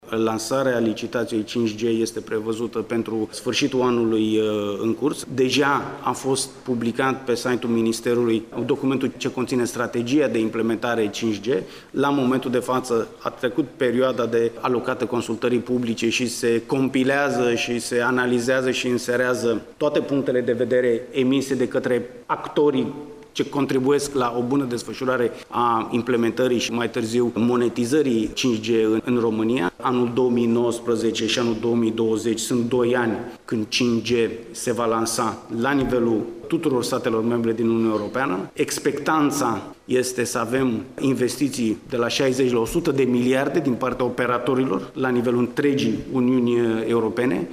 Anunţul a fost făcut astăzi, la Iaşi, de ministrul Comunicațiilor și Societății Informaționale, Alexandru Petrescu în cadrul conferinţei e-government.